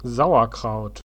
Sauerkraut (/ˈs.ərˌkrt/; German: [ˈzaʊ.ɐˌkʁaʊt]
De-sauerkraut.ogg.mp3